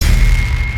Maincharactershot.mp3